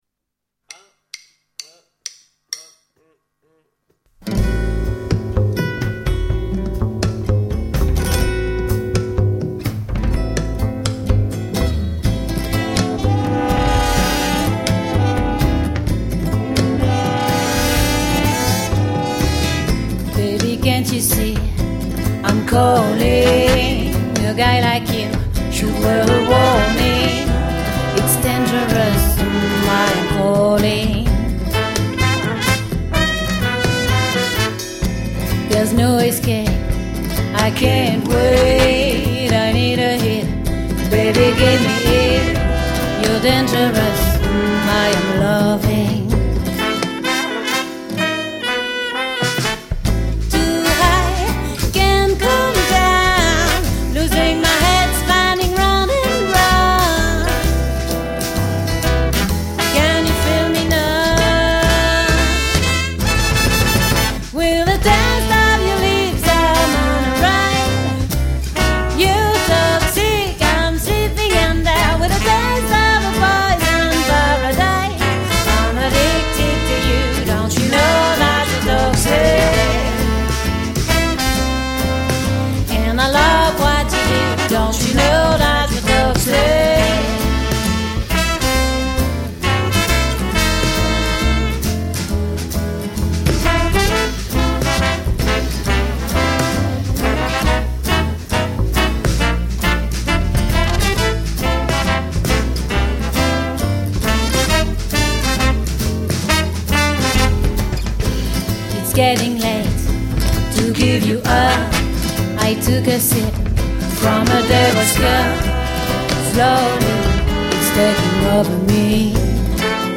Jazz Swing Pop